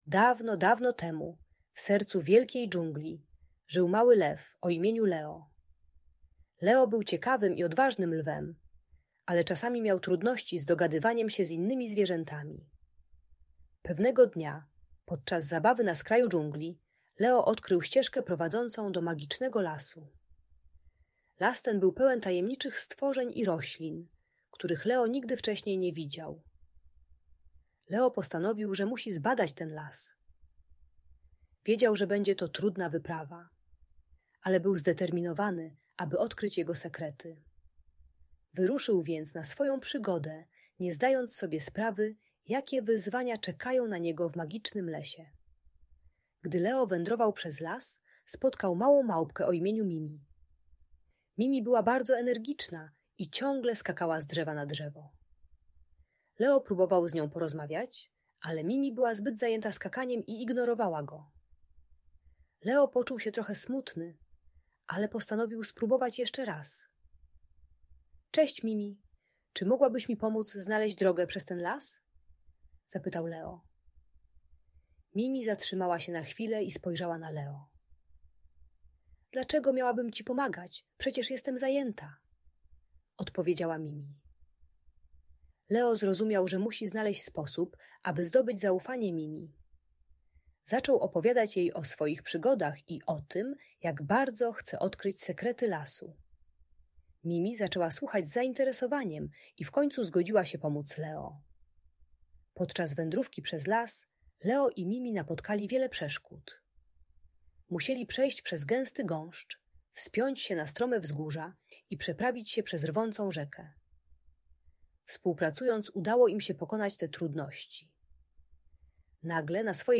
Leo i magiczny las - wciągająca historia o przygodach - Audiobajka